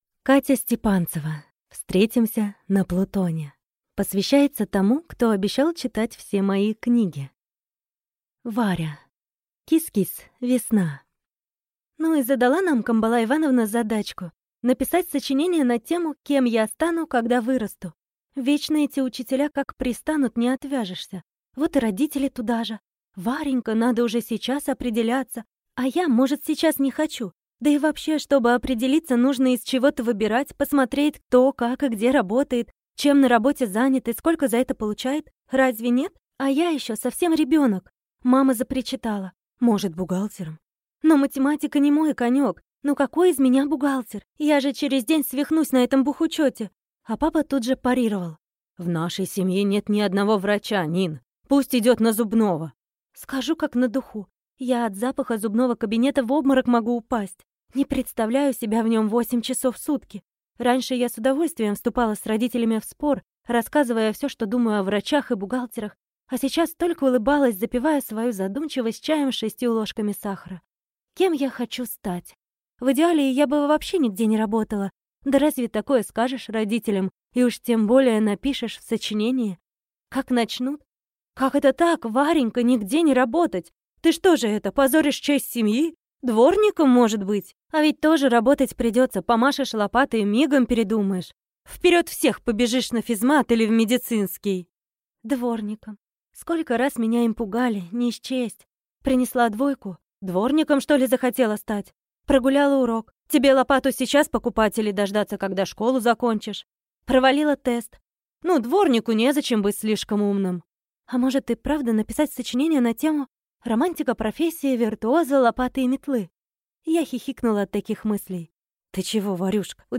Аудиокнига Встретимся на Плутоне | Библиотека аудиокниг